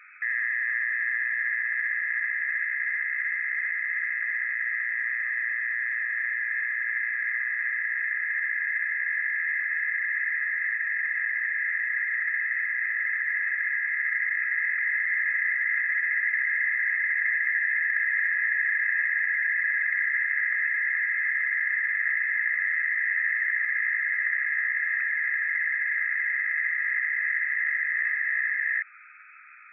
Начало » Записи » Радиоcигналы на опознание и анализ
Сигнал с любительской сороковки
signal_s_lubiteliskoj_sorokovki.wav